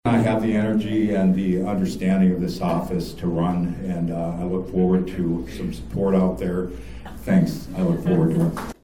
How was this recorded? At their 2022 State Convention over the weekend (July 8-9, 2022) in Fort Pierre, the South Dakota Democratic Party nominated candidates for constitutional offices, adopted its platform, adopted an amendment to the constitution and passed resolutions.